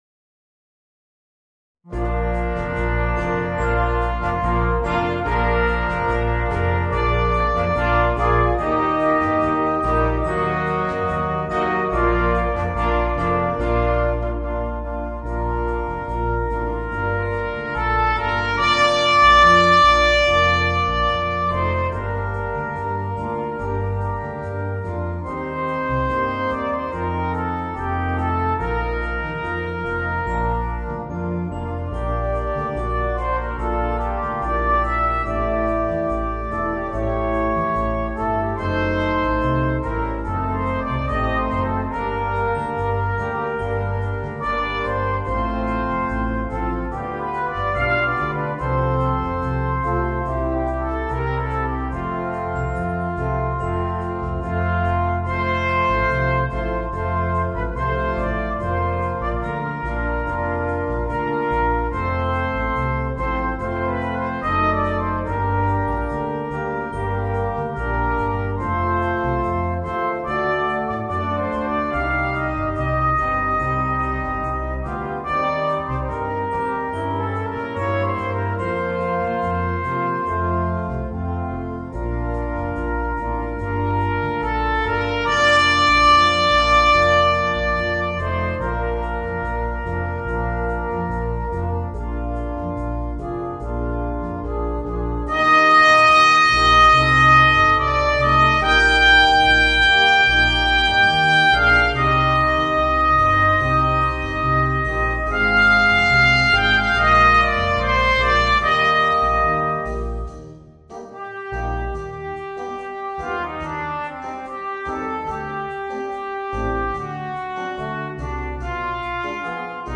Voicing: 2 Trumpets, Horn, Trombone and Tuba